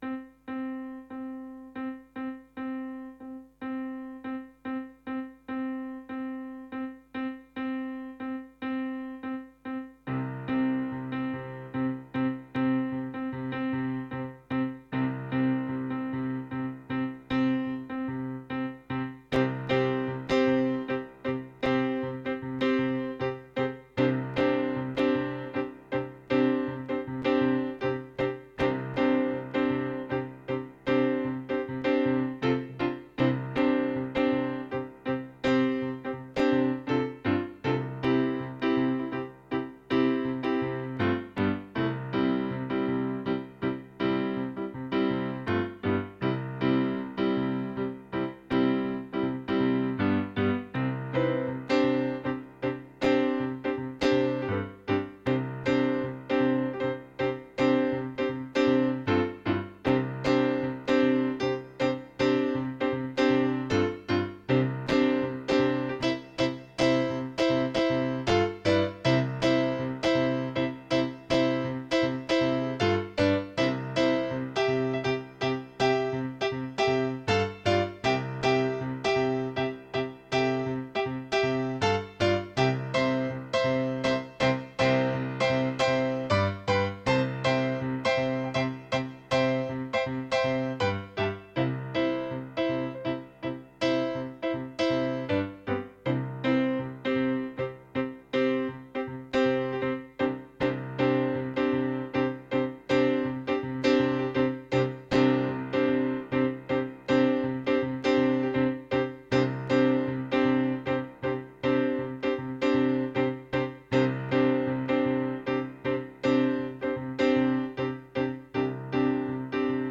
– or two 6/4-time bars, which in my opinion makes more sense both musically and when looking at the logo image:
It’s not a full composition, and I haven’t really internalized the rhythm yet, but still I think it’s rather pretty, and a bit catchy.
That’s probably also why I dropped a note and lapsed into 5/4 time a few times – sorry.)